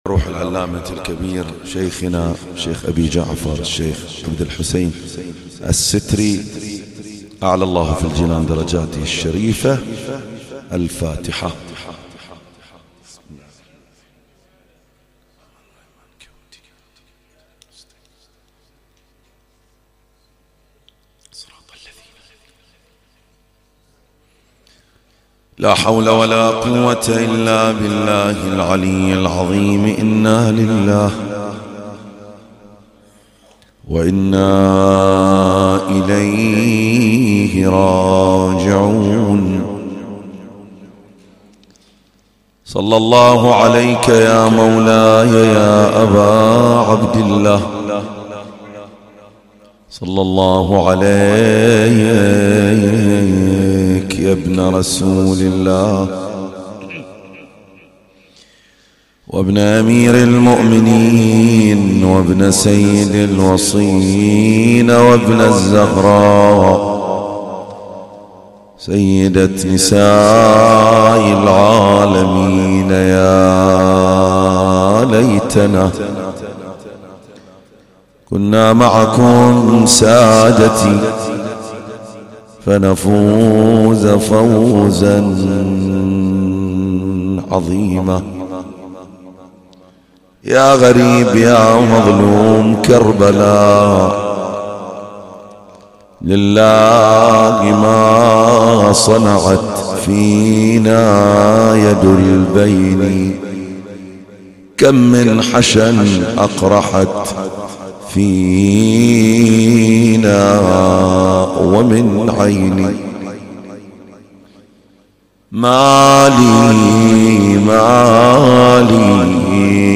تغطية صوتية: الليلة الأولى – الليالي الحسنية 1440هـ
الخطيب